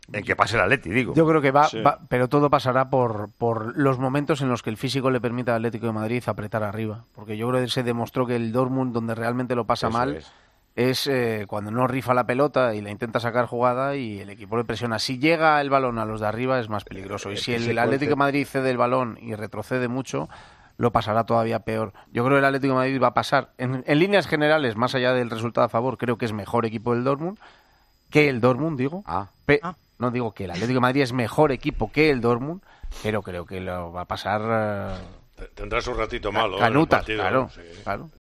El comentarista de Tiempo de Juego y de El Partidazo de COPE habla del encuentro de vuelta del conjunto rojiblanco en Alemania ante el Borussia Dortmund.
Anoche, en una nueva entrega de El Tertulión de los domingos con Juanma Castaño, nuestro compañero Gonzalo Miró dio las claves para que el Atlético de Madrid avance a la siguiente ronda de la Champions League.